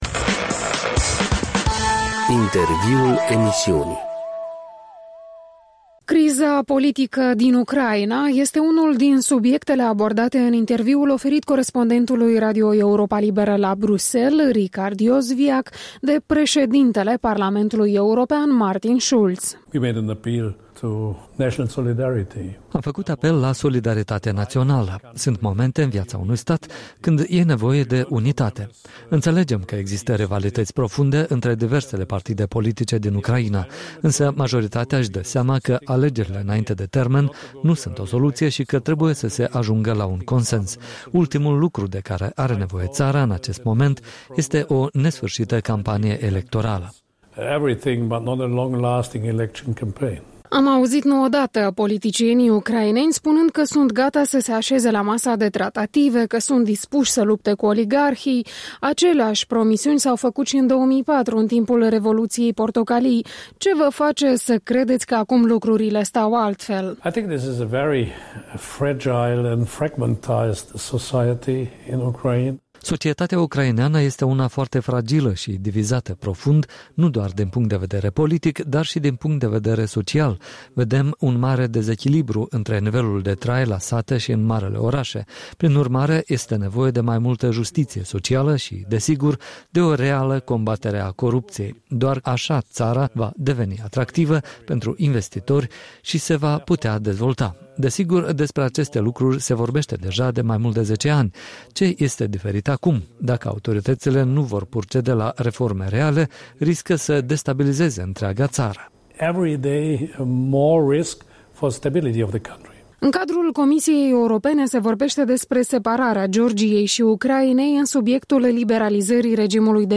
Un interviu cu președintele Parlamentului European acordat Europei Libere.
Un interviu în exclusivitate cu președintele Parlamentului European